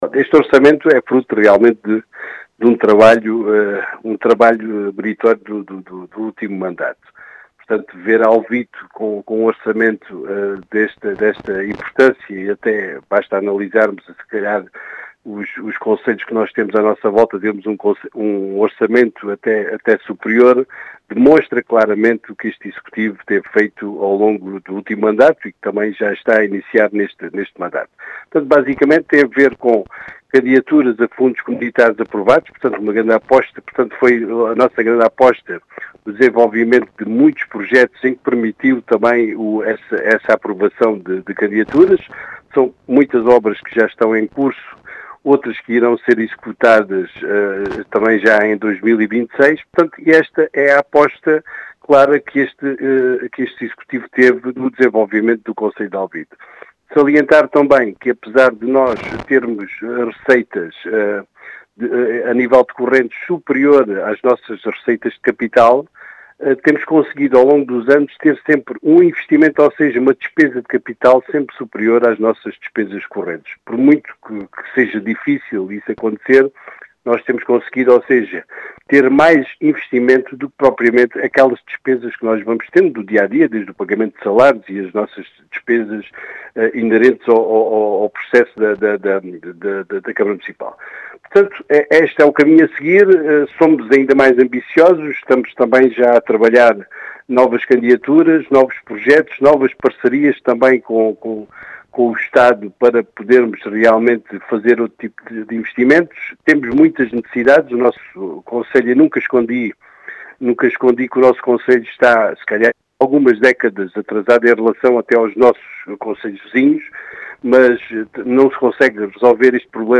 As explicações são do presidente da Câmara Municipal de Alvito, José Efigénio, que realça a importância e a dimensão deste orçamento, e deixa a ambição para o futuro do concelho.